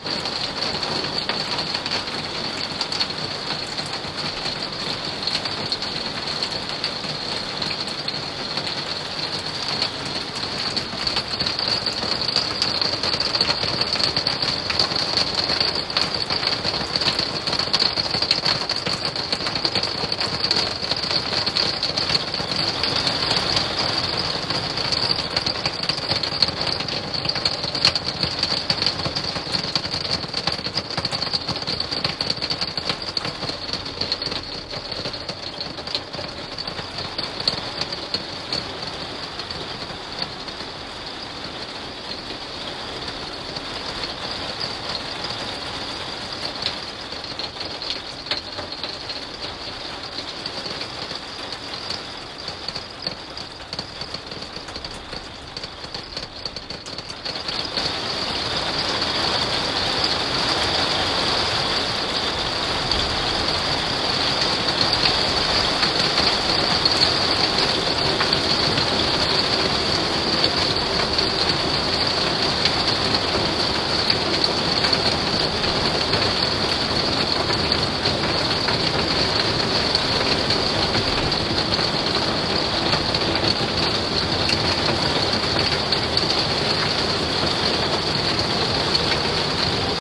環境音 ジャジャ降り / 雨